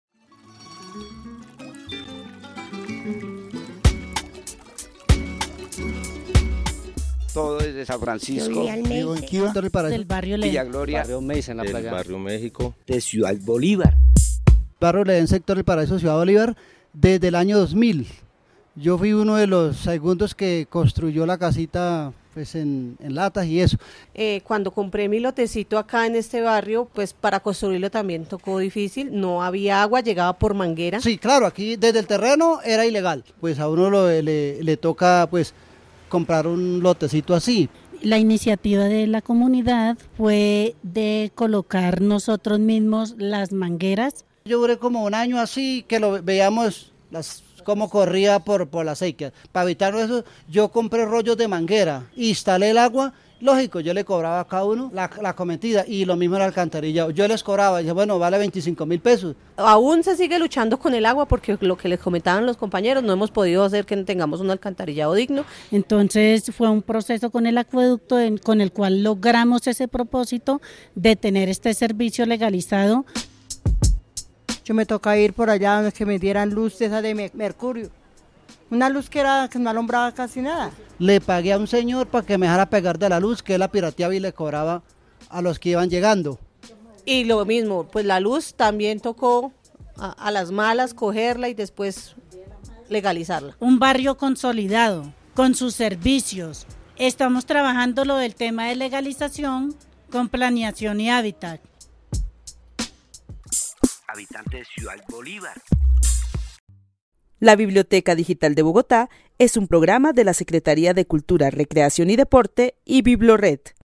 Testimonios sobre el barrio El Edén y su relación histórica con la localidad al ser uno de los barrios más jóvenes de Ciudad Bolívar.